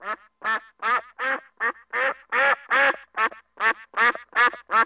جلوه های صوتی
دانلود صدای اردک برای کودکان از ساعد نیوز با لینک مستقیم و کیفیت بالا